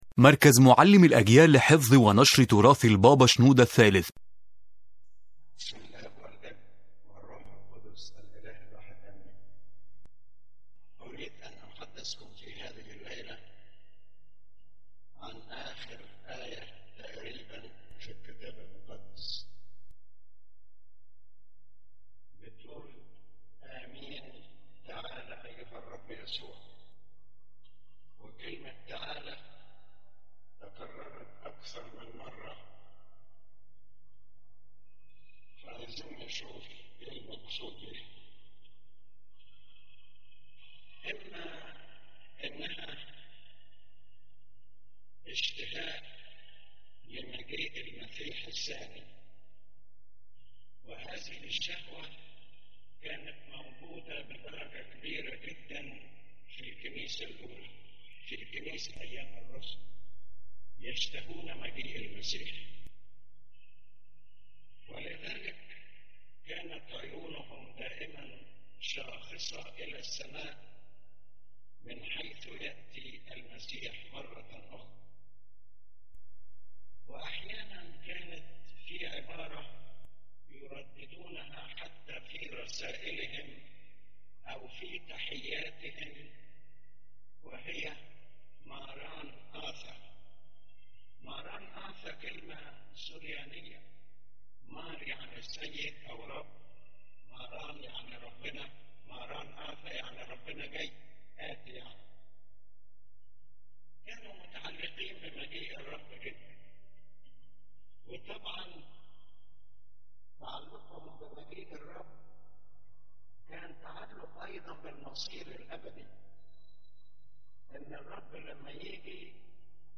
The lecture revolves around the phrase “Amen, Come O Lord Jesus” as a deep prayer carrying multiple meanings in spiritual life, reflecting man’s longing for God and readiness to meet Him at all times.